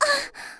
damage_2.wav